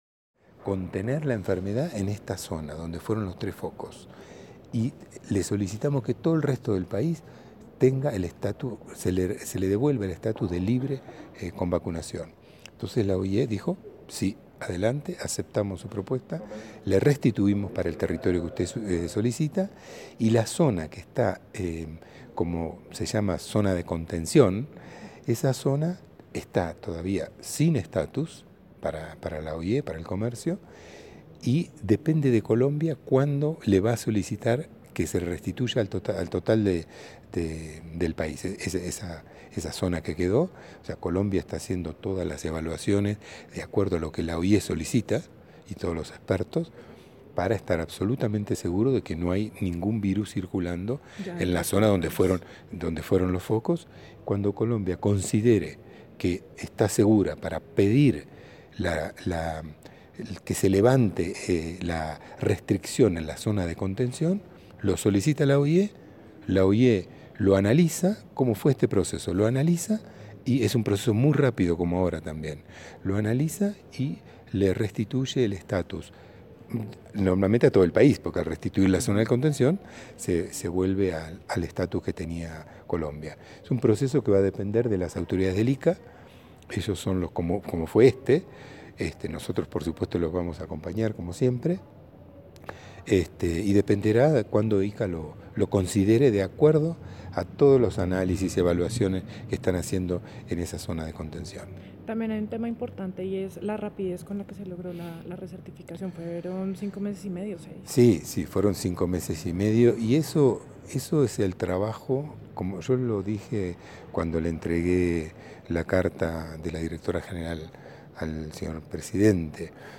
Rueda de prensa en la Presidencia de la República